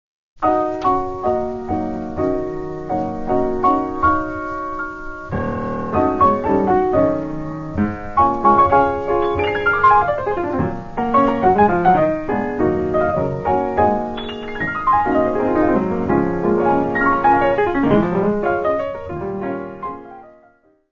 Music Category/Genre:  Jazz / Blues